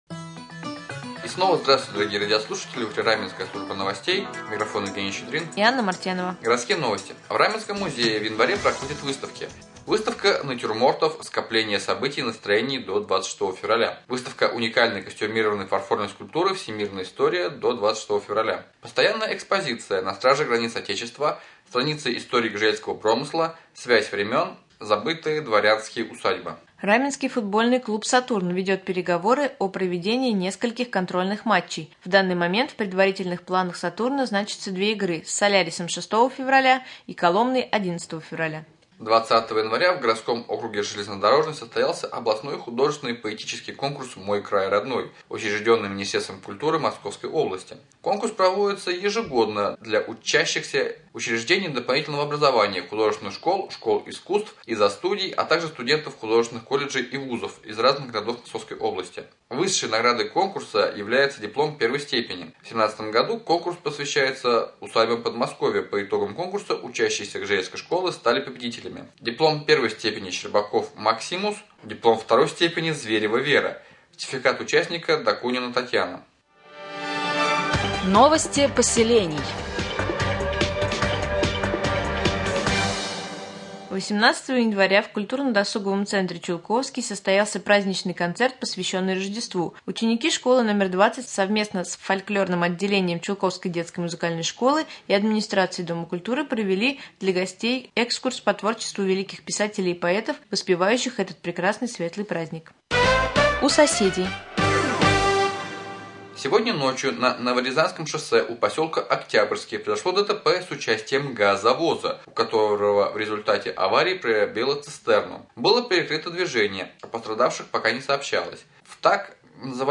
В прямом эфире депутаты Совета депутатов Раменского муниципального района: глава г.п.Кратово Алексей Александрович Емельянов и председатель Совета депутатов г.п.Кратово Иван Васильевич Борисенко.